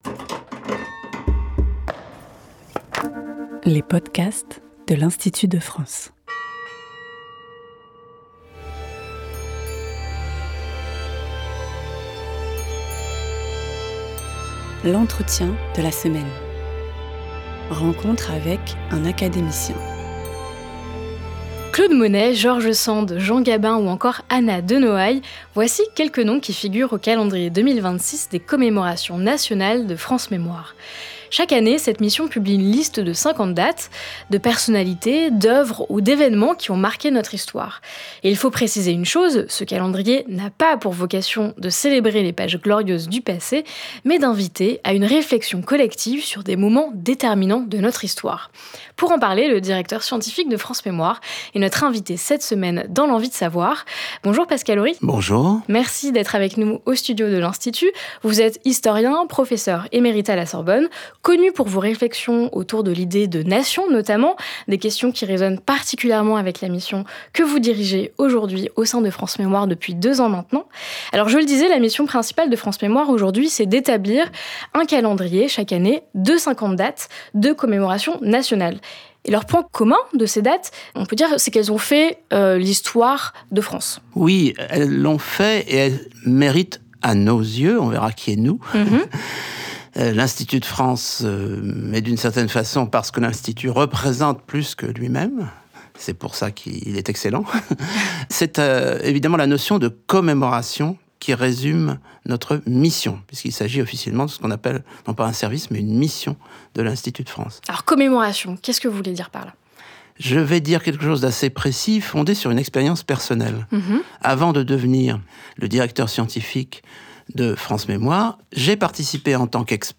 Son directeur scientifique, l’historien Pascal Ory, en présente les principaux enjeux et revient sur quelques dates emblématiques de l’édition 2026.